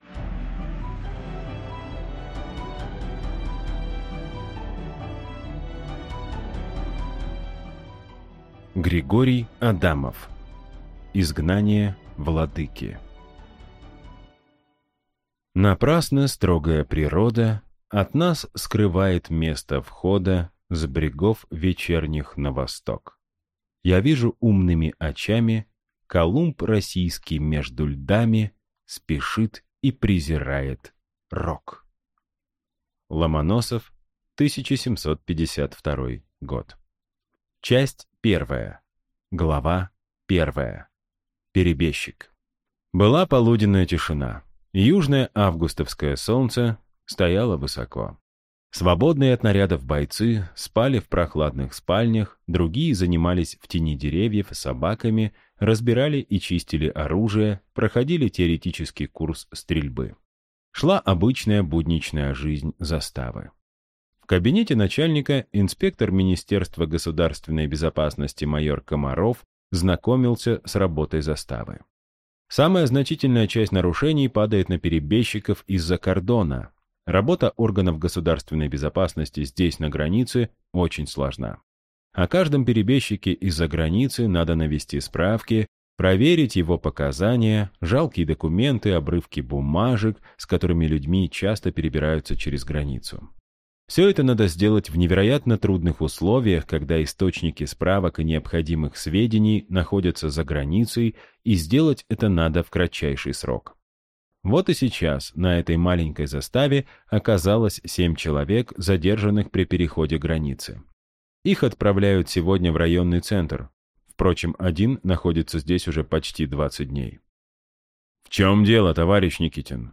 Аудиокнига Изгнание владыки | Библиотека аудиокниг